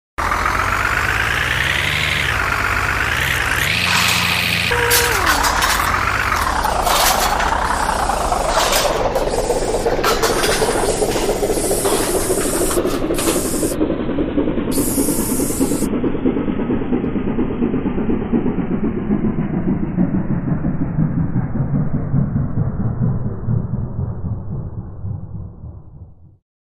Звуки поломки автомобиля
Звук ломающейся мультяшной машины